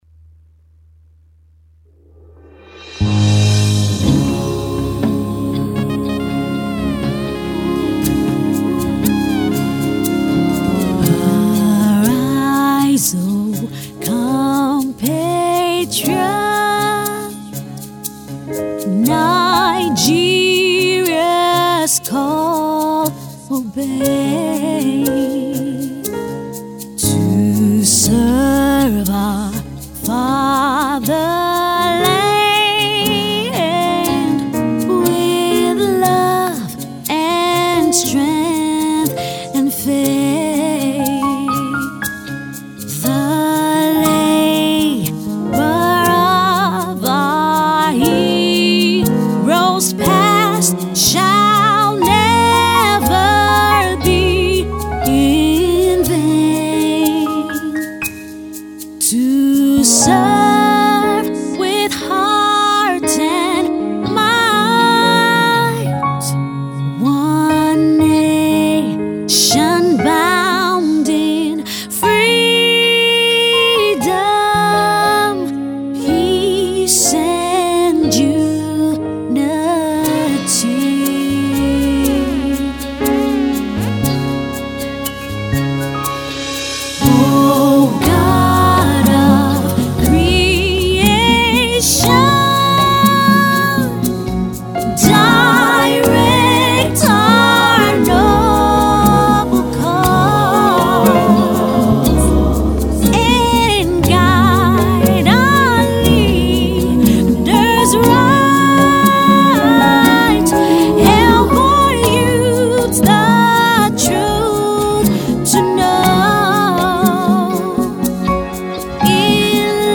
in tune with today’s contemporary feel and sound